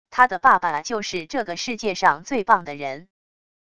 他的爸爸就是这个世界上最棒的人wav音频生成系统WAV Audio Player